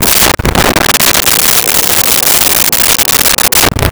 Glass On Bar With Slide 04
Glass On Bar With Slide 04.wav